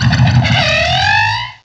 pokeemerald / sound / direct_sound_samples / cries / yanmega.aif